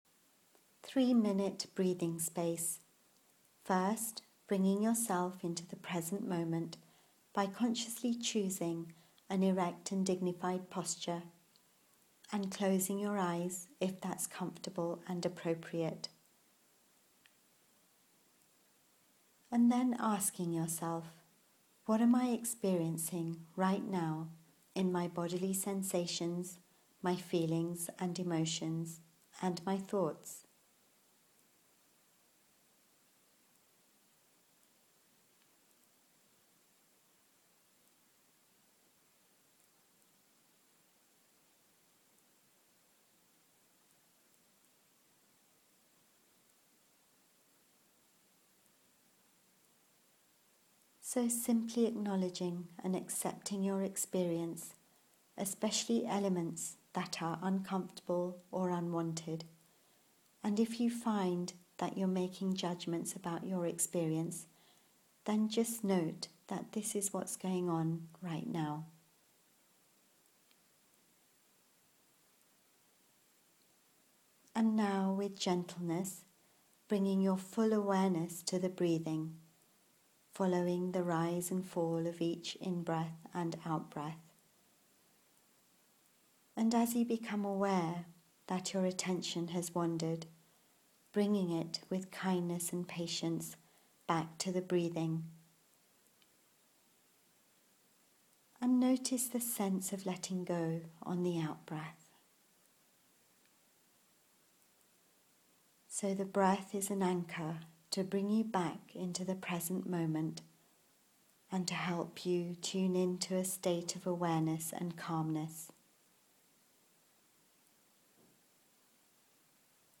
• Guided Audio Meditations